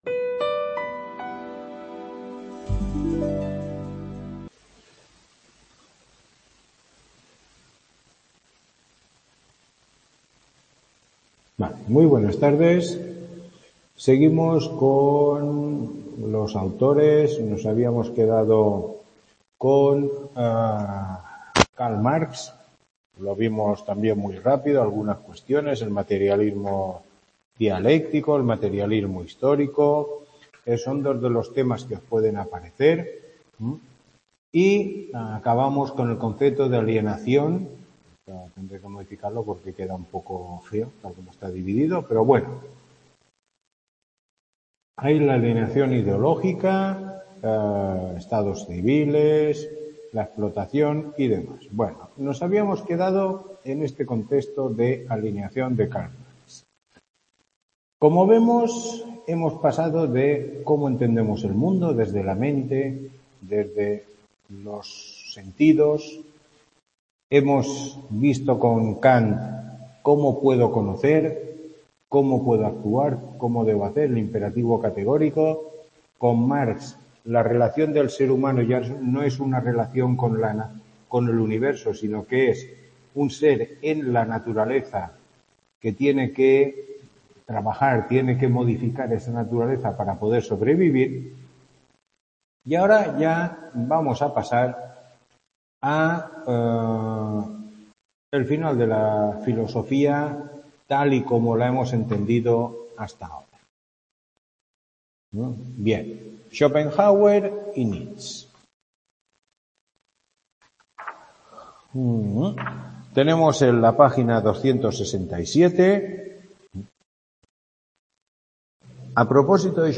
Tutoría 05